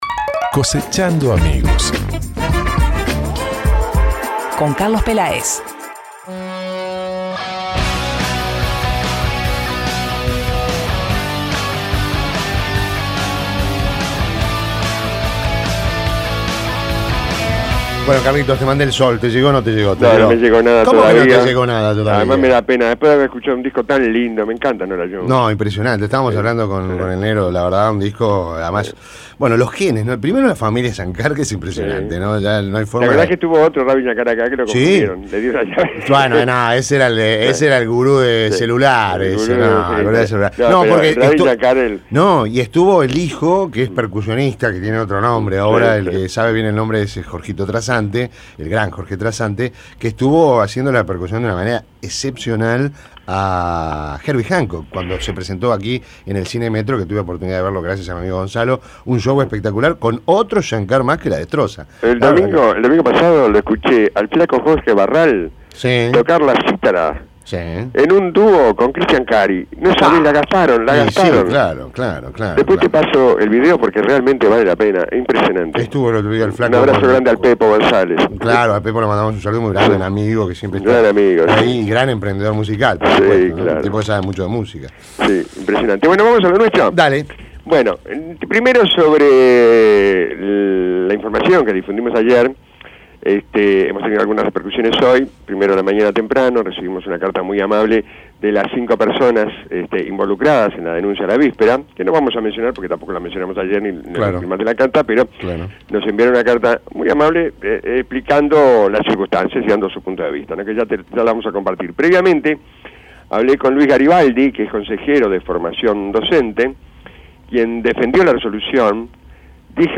Títulos en cuestión: a propósito de la información que difundimos ayer sobre denuncias de docentes a propósito de títulos docentes, según ellos, mal otorgados recibimos una carta de los docentes denunciados y hablamos con Luis Garibaldi integrante del Consejo de Formación en Educación de ANEP.